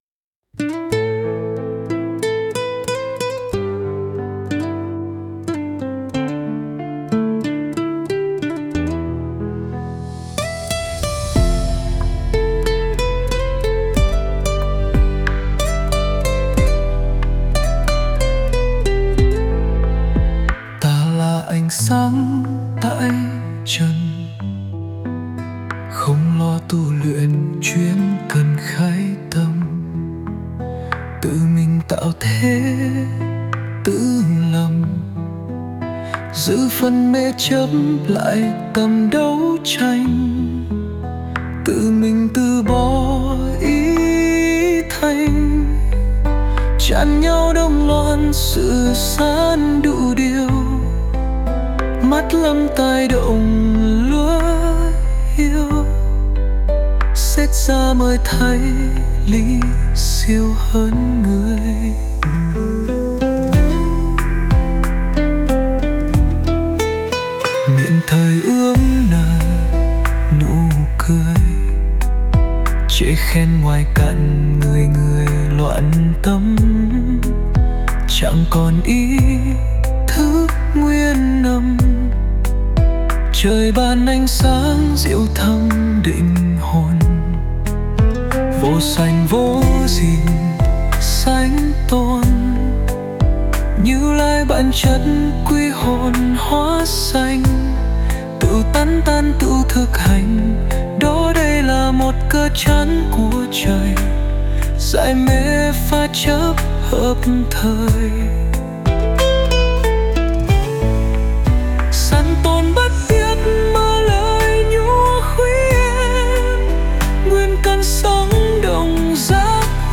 098-Anh-sang-02-nam-cao-hay-hon.mp3